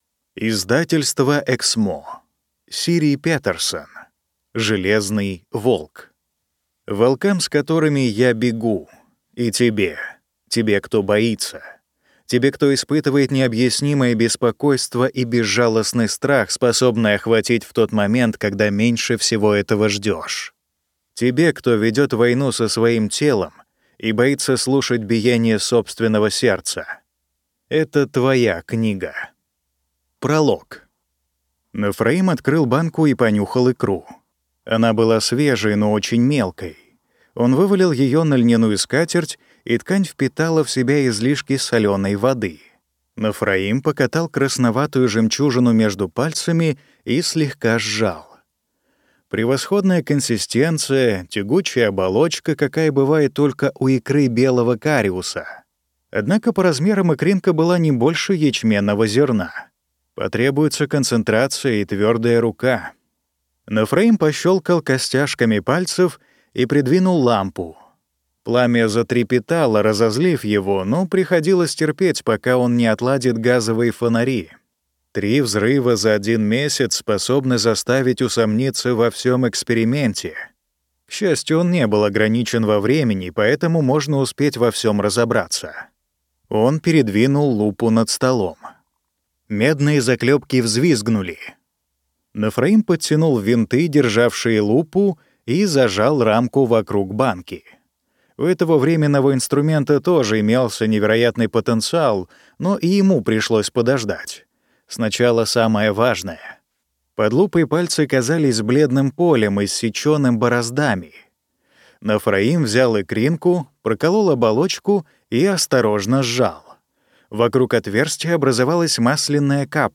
Аудиокнига Железный волк | Библиотека аудиокниг